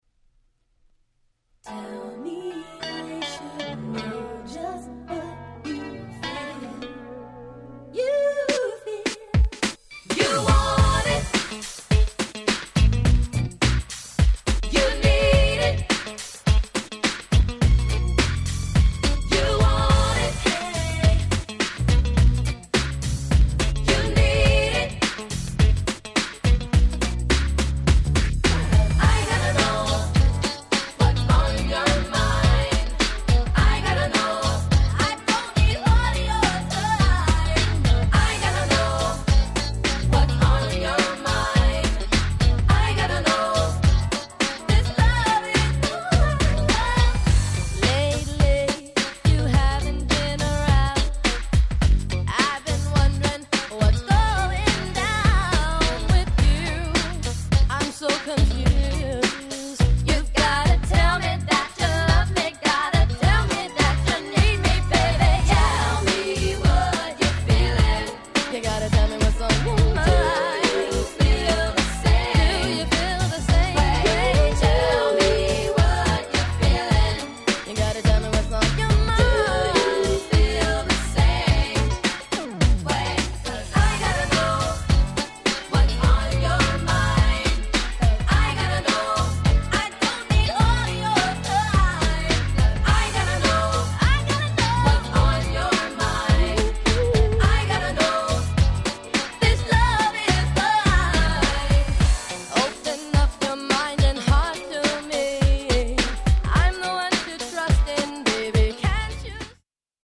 【Media】Vinyl 12'' Single (Promo)
パンチ力が少し弱いのはわかります。
プロモオンリー  90's ニュージャックスウィング NJS キャッチー系